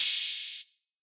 SOUTHSIDE_open_hihat_clean_long.wav